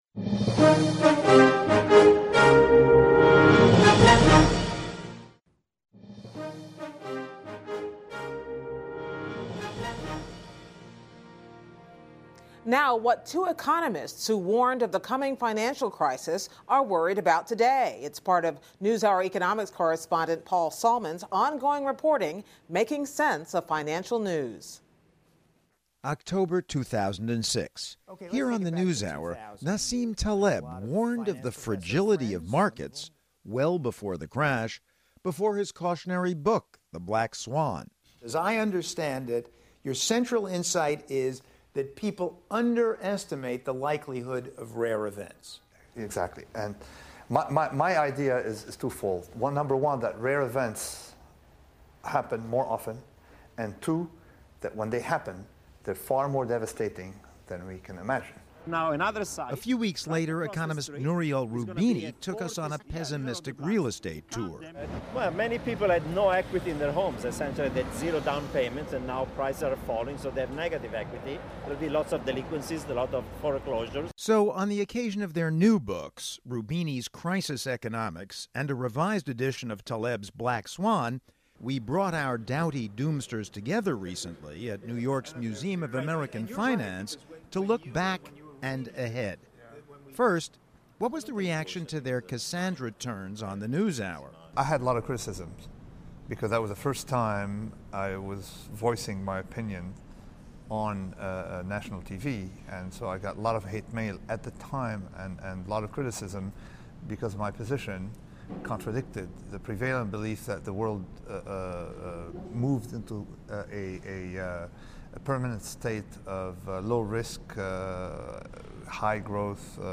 I’ve interviewed Nassim Taleb of “Black Swan” fame three times on the NewsHour — here, there, and back here.